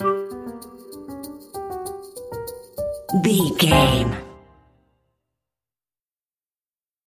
Uplifting
Aeolian/Minor
flute
oboe
strings
orchestra
cello
double bass
percussion
silly
circus
goofy
cheerful
perky
Light hearted
quirky